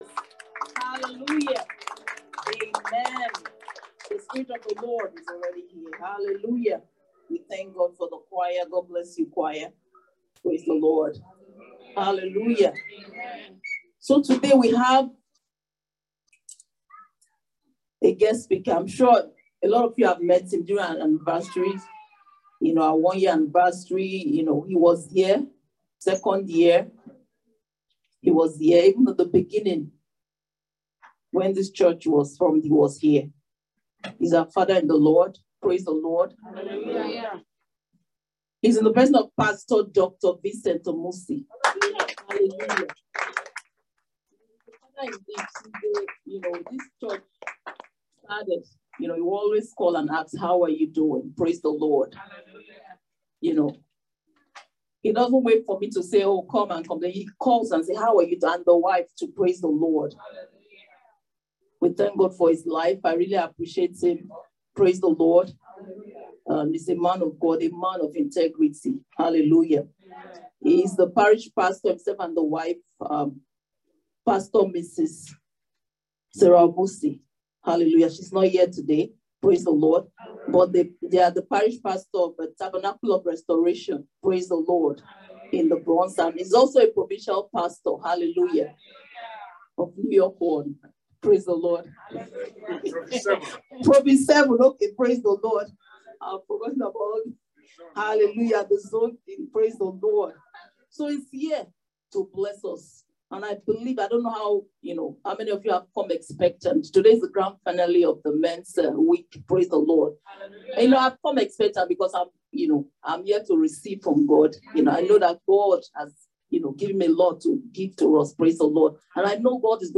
Service Type: Thanksgiving Service